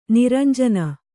♪ niranjana